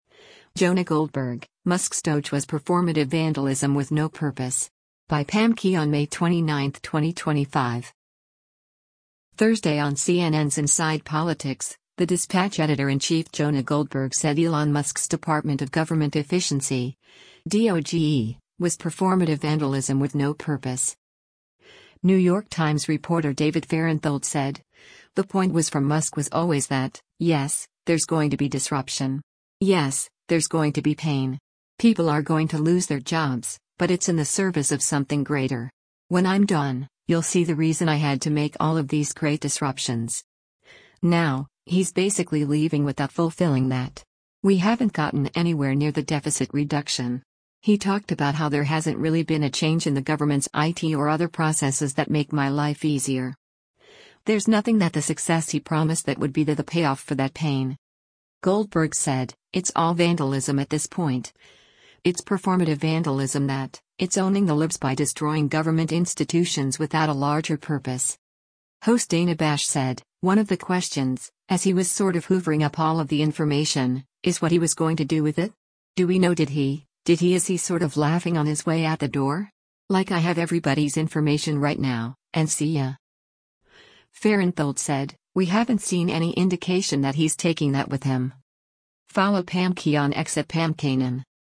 Thursday on CNN’s “Inside Politics,” The Dispatch editor-in-chief Jonah Goldberg said Elon Musk’s Department of Government Efficiency (DOGE) was “performative vandalism” with no purpose.